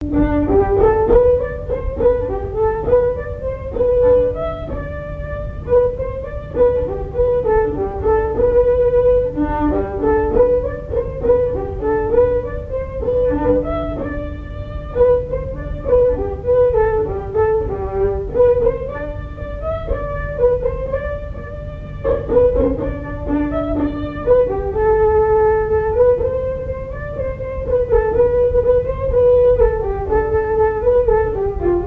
danse : mazurka-valse
Airs à danser aux violons et deux chansons
Pièce musicale inédite